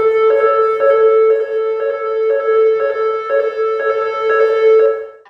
Evacuation Alarm #1
Sound of the evacuation siren in a concert hall, triggered by an electrical issue. Each such sequence is normally interspersed with a message in French and English, urging people to leave the building, but I have removed it.
UCS Category: Alarms / Electronic (ALRMElec)
Type: Soundscape
Channels: Monophonic
Conditions: Indoor
Realism: Realistic